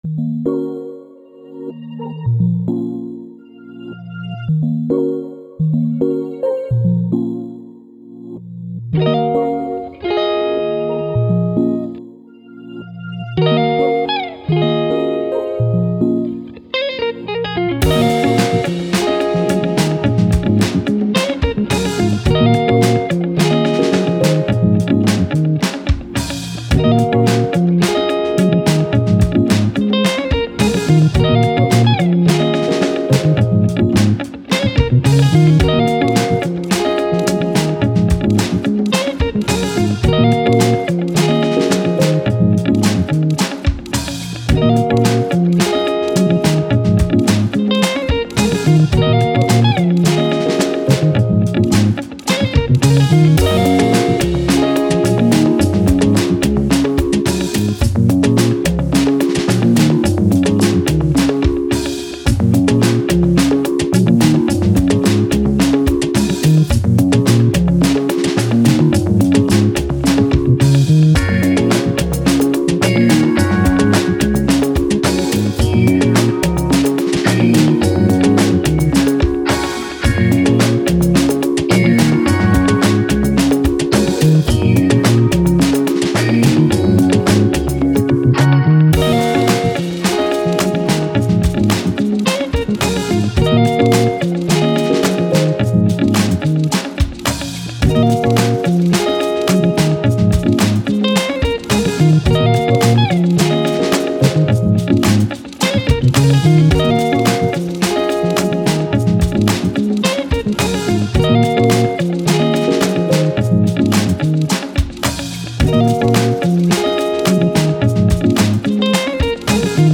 Downtempo, Trip Hop, Soul, Journey